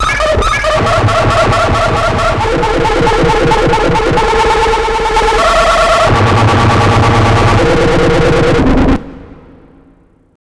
OLDRAVE 7 -R.wav